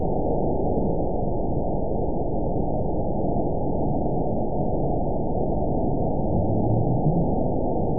event 922707 date 03/15/25 time 16:32:39 GMT (1 month, 2 weeks ago) score 8.97 location TSS-AB02 detected by nrw target species NRW annotations +NRW Spectrogram: Frequency (kHz) vs. Time (s) audio not available .wav